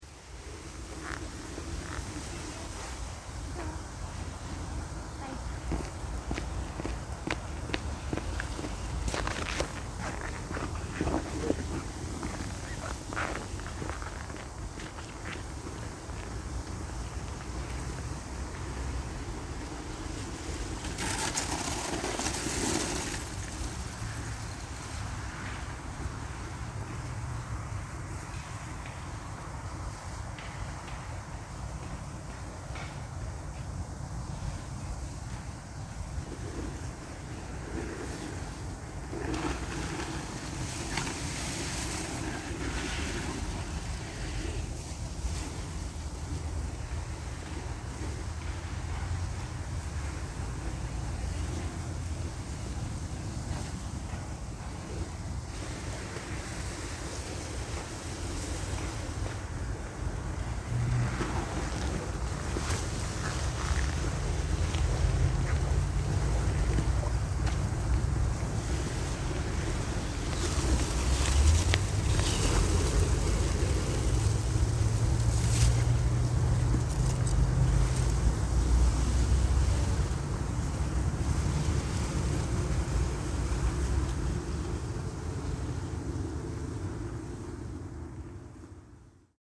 As I was approaching, I saw these amazing ice cakes slowly drifting along and spinning around making the coziest squeaky sounds. I just stood there with my phone and observing in awe.